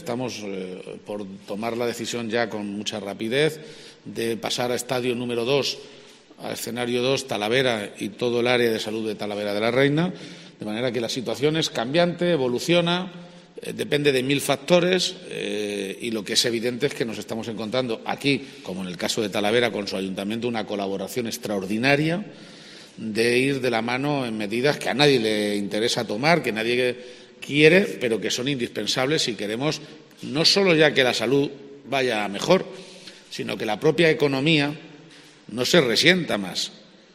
Declaraciones del Presidente de Castilla-La Mancha esta mañana en Guadalajara, en las que ha destacado la extraordinaria colaboración del Ayuntamiento de la Ciudad de la Cerámica.